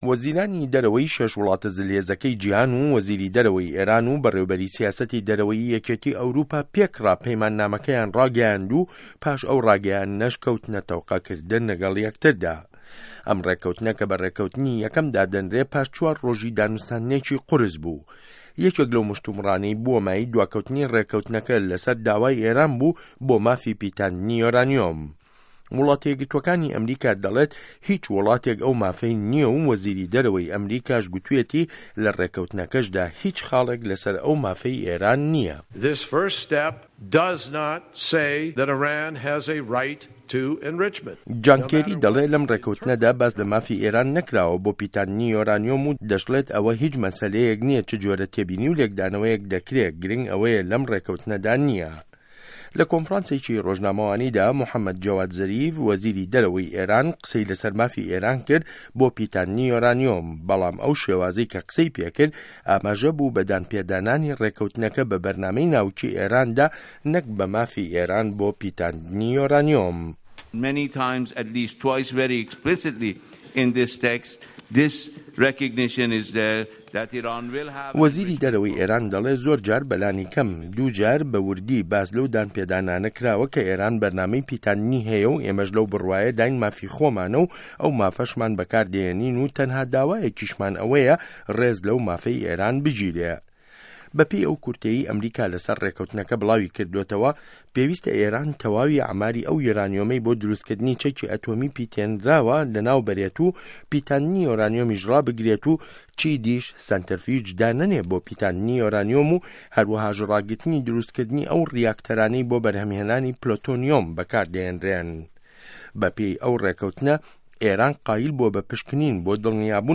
ڕاپۆرت له‌سه‌ر ڕێکه‌وتنی به‌رایی کۆمه‌ڵگای نێونه‌ته‌وه‌یی و ئێران